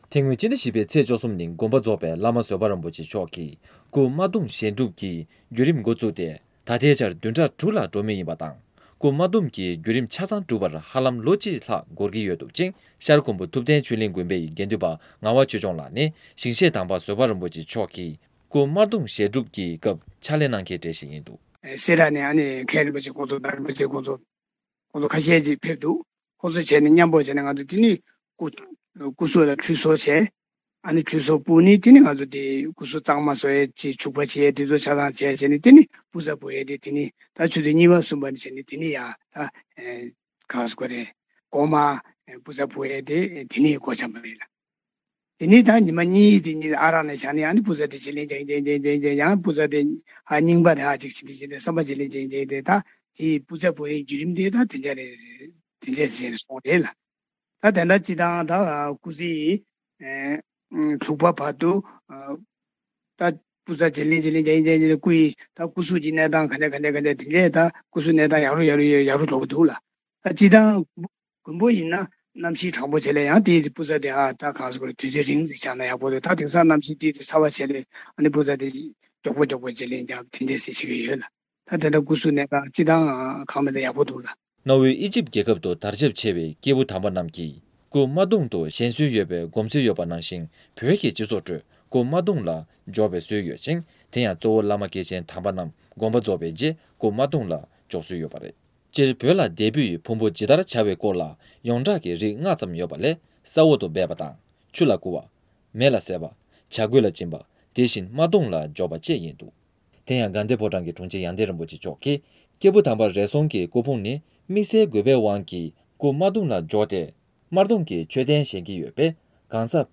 བོད་ཀྱི་བླ་མ་སྐྱེས་ཆེན་དམ་པ་རྣམས་ཀྱི་སྐུ་དམར་གདུང་བཞེངས་སྲོལ་དང་བརྒྱུད་རིམ་སྐོར་བཅར་འདྲི་ངོ་སྤྲོད།